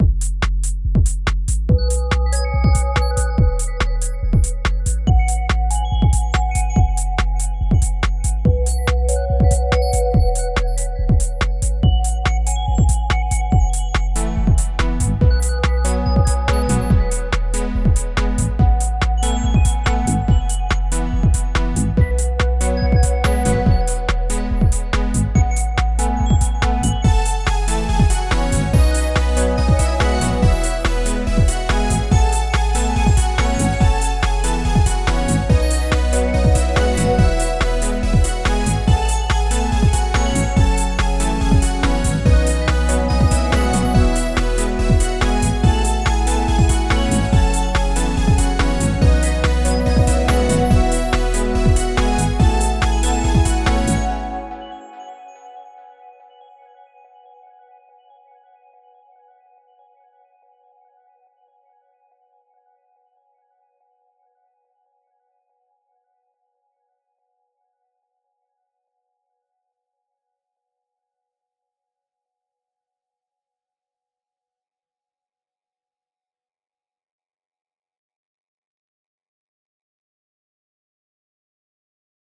So I make these in Ableton Note on my phone.
I never once stopped it, so I never noticed that I had the entire loops offset by half until it was far too late and I was basically done.
What happens when the third bar in a four bar loop structure accidentally turns into the first?